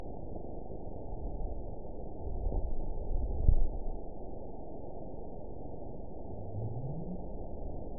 event 920431 date 03/25/24 time 00:40:39 GMT (1 year, 1 month ago) score 9.68 location TSS-AB01 detected by nrw target species NRW annotations +NRW Spectrogram: Frequency (kHz) vs. Time (s) audio not available .wav